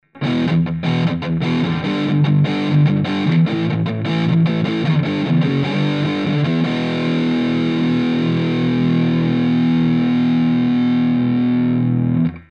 Пример звука Marshall AVT-20 (овердрайв)
Записан недорогим микрофоном Philips MD 600. В Sound Forge подрезаны высокие частоты.
Записано на гитаре Fender Squier